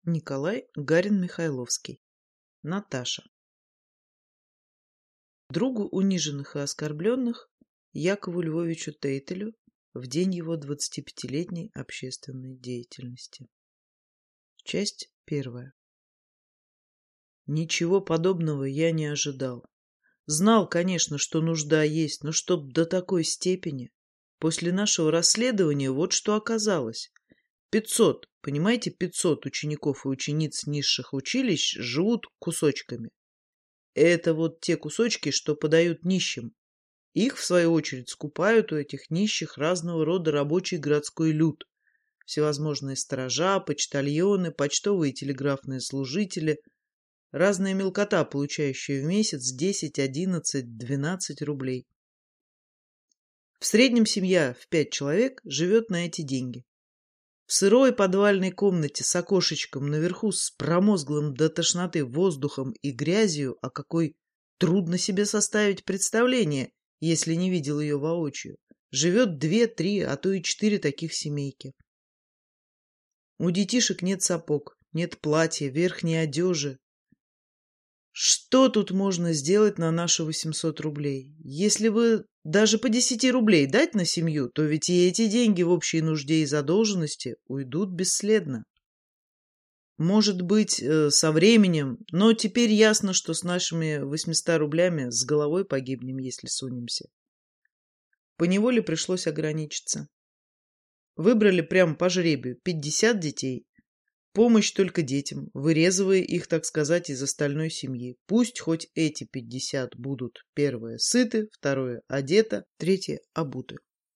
Аудиокнига Наташа | Библиотека аудиокниг